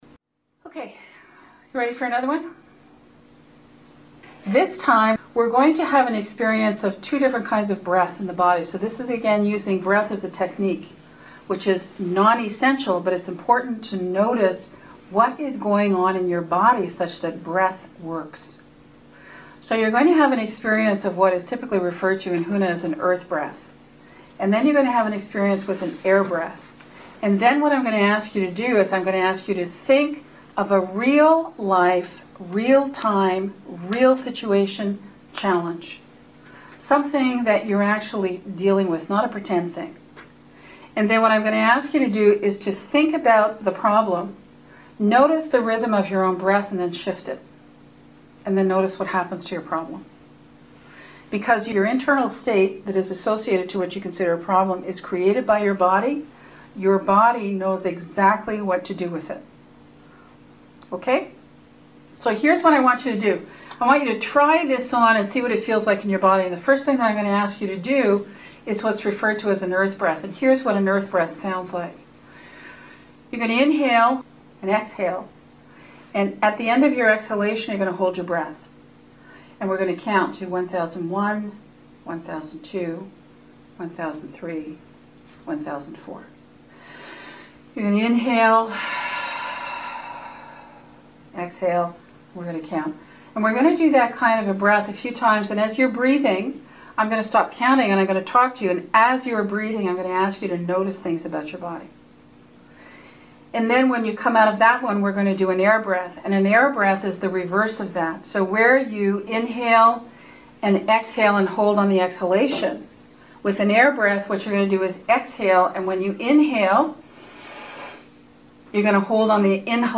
PLEASE NOTE: The guided reflection portion of this recording should be engaged only when you are able to be fully present.